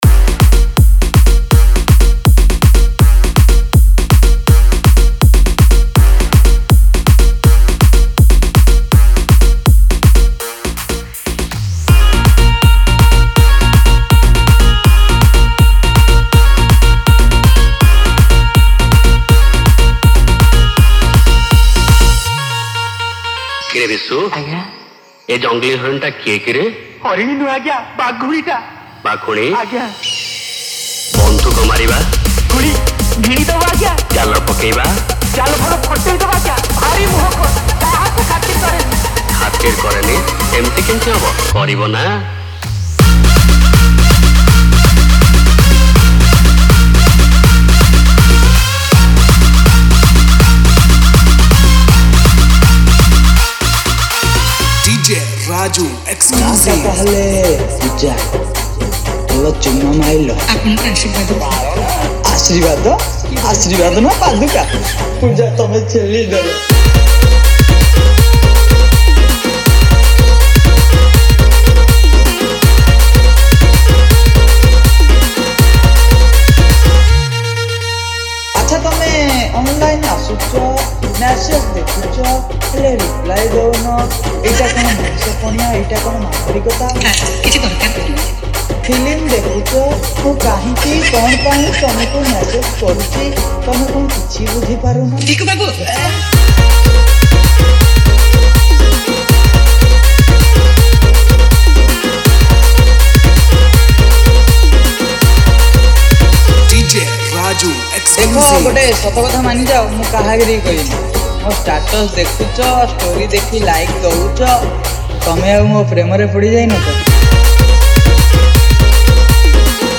Single Dj Song Collection 2023 Songs Download